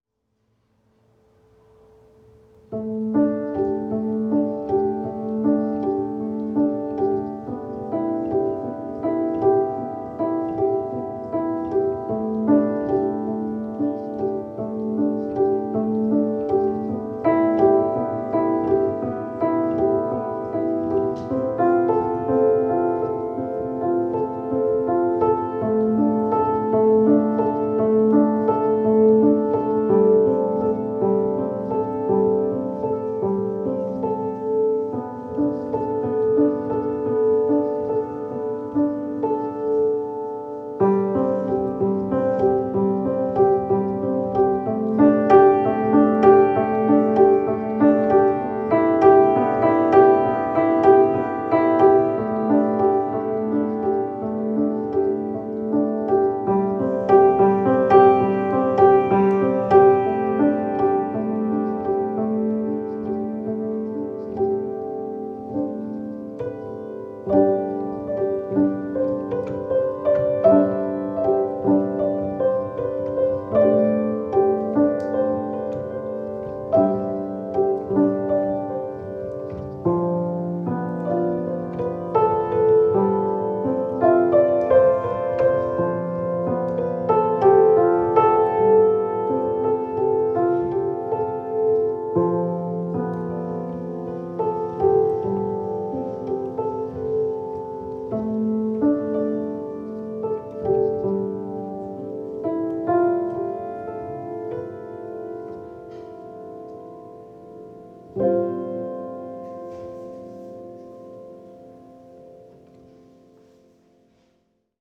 muzyka